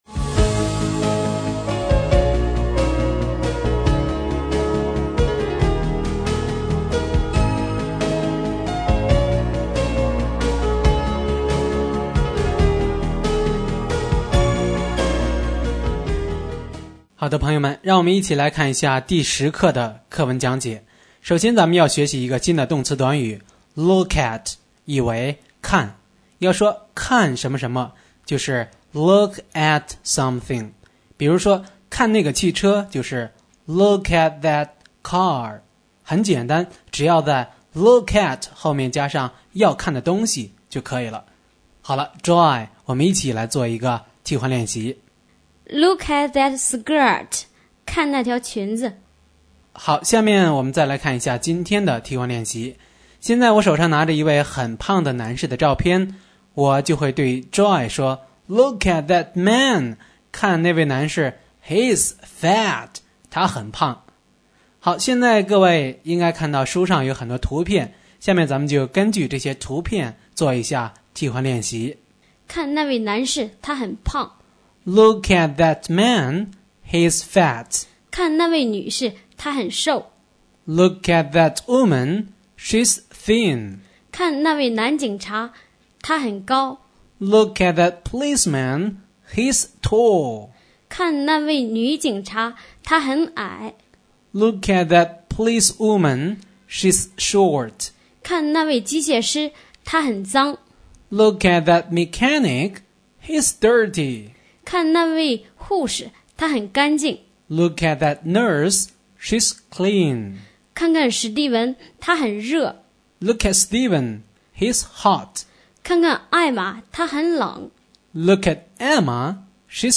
新概念英语第一册第10课【课文讲解】